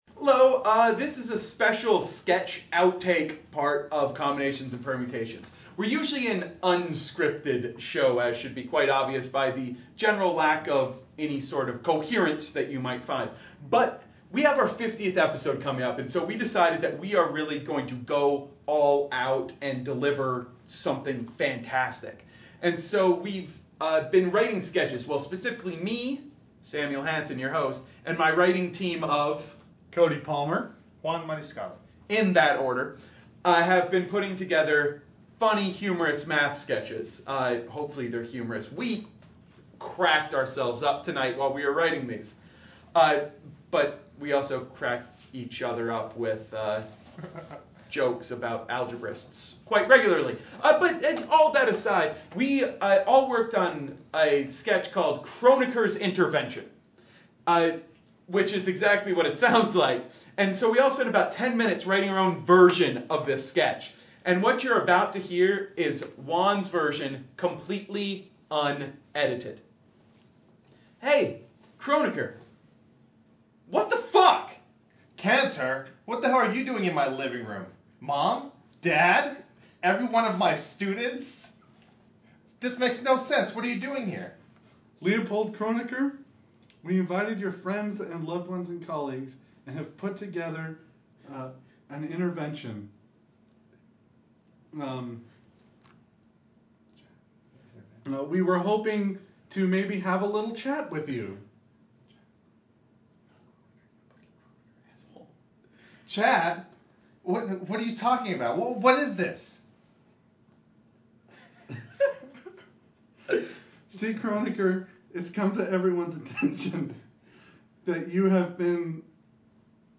Combinations and Permutations 50th Episode Sketch Outtakes